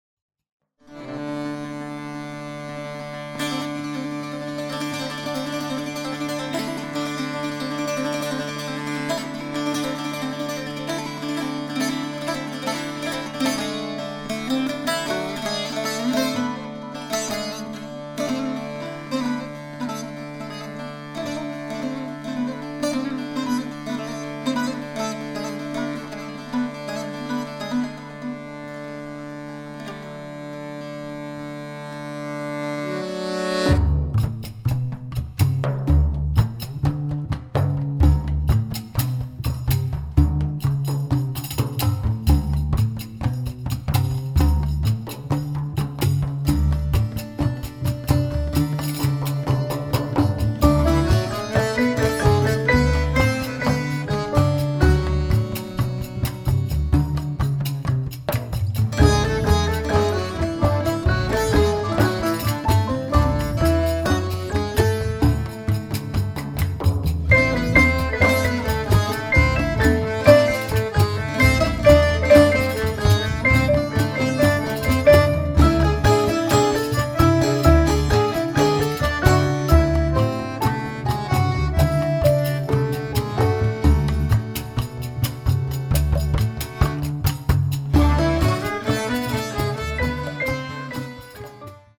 Accordian, Piano, saz, boddhran, rig, dud-boo, violin